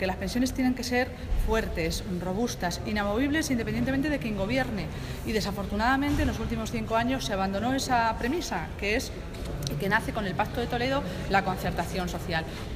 La portavoz socialista ha participado en una jornada sobre el sistema público de pensiones, organizada por UGT Castilla-La Mancha que ha tenido lugar en Albacete
Cortes de audio de la rueda de prensa